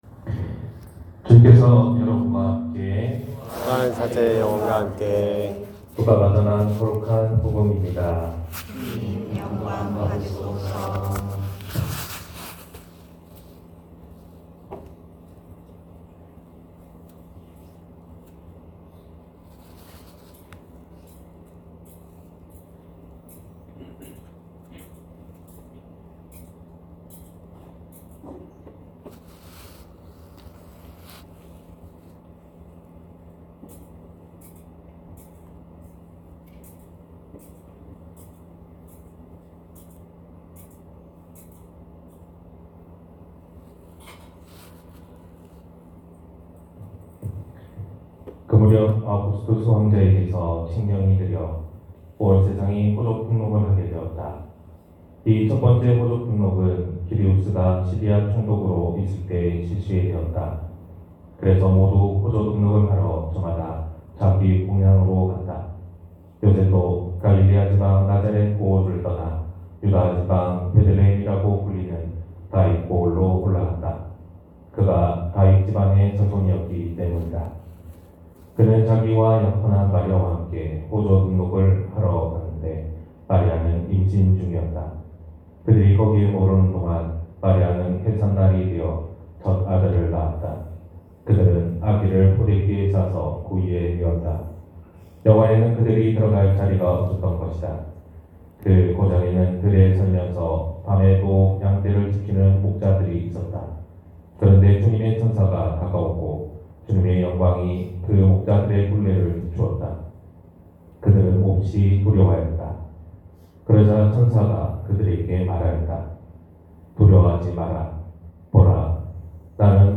251225 성탄미사 강론말씀